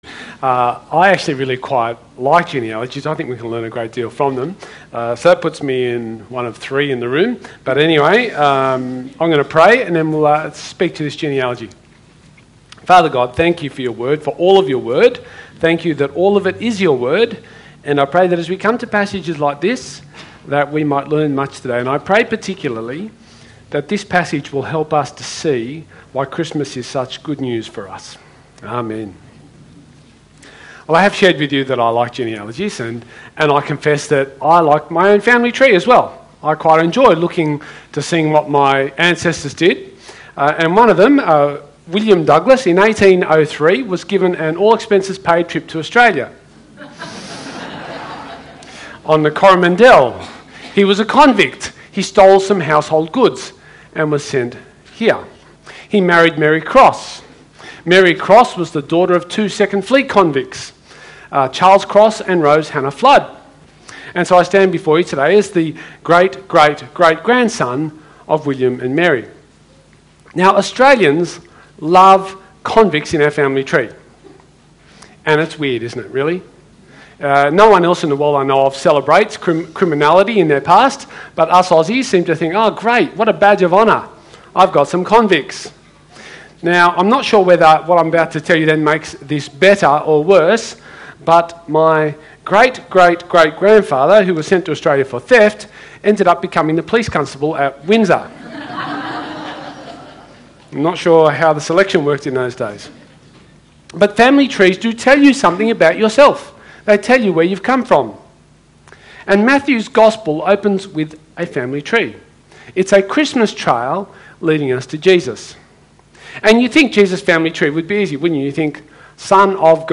SERMON – Christmas Eve